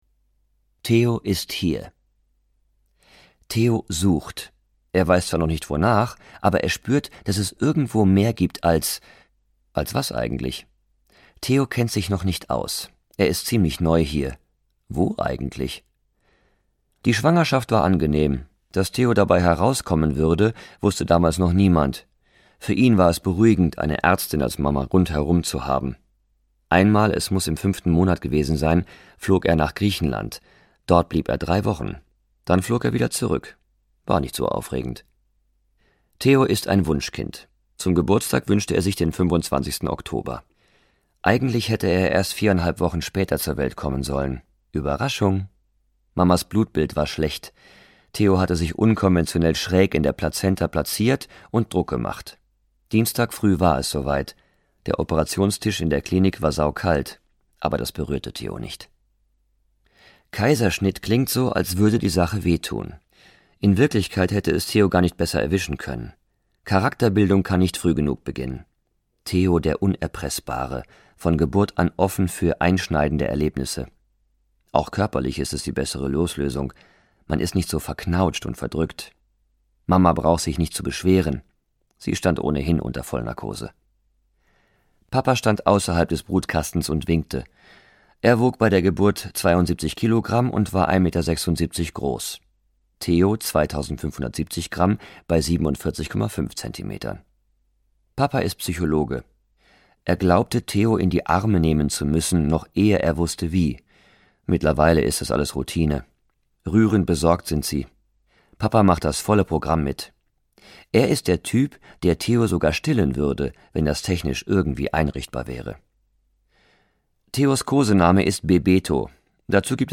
Theo - Daniel Glattauer - Hörbuch